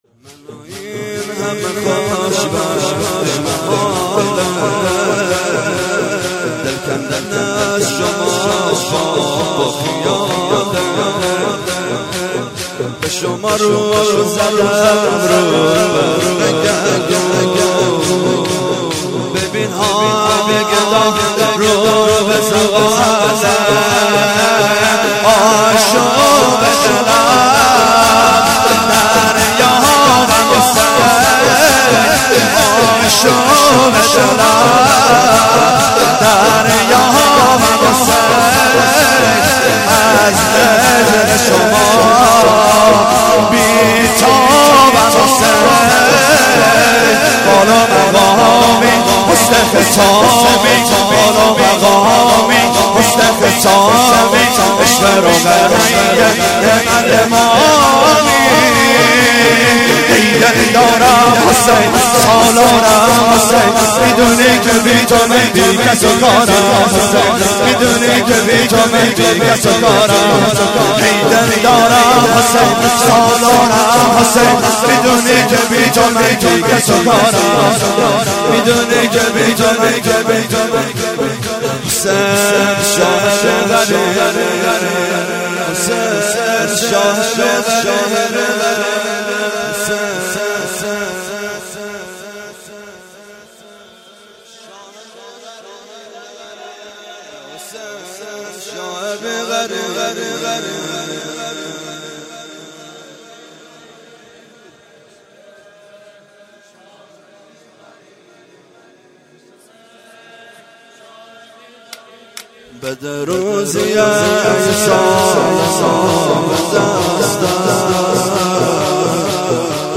شور - منواین همه خوشبختی محاله
جلسه هفتگی